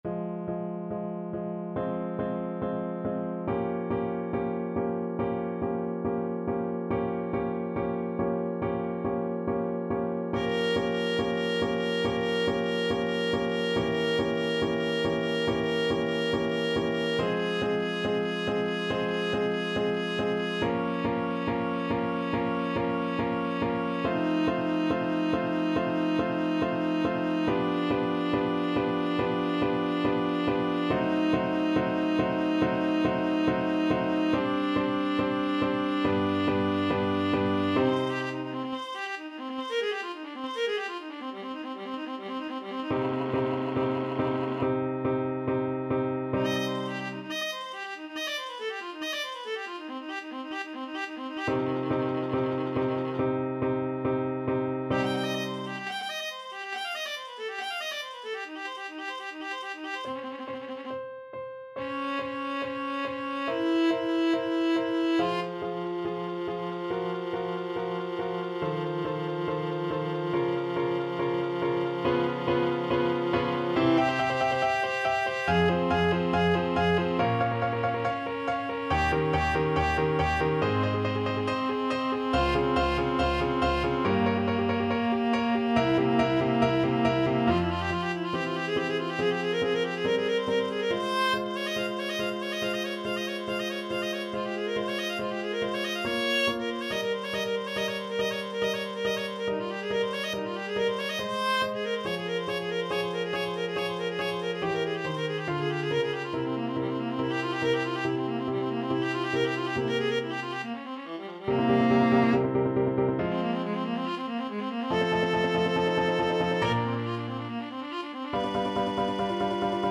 Viola version
4/4 (View more 4/4 Music)
~ = 70 Allegro non molto (View more music marked Allegro)
Viola  (View more Advanced Viola Music)
Classical (View more Classical Viola Music)